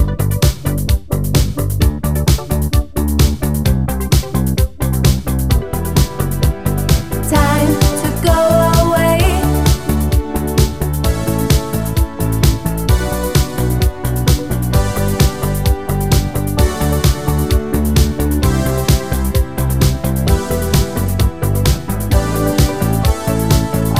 No Guitars or Solo Synth Pop (1980s) 3:56 Buy £1.50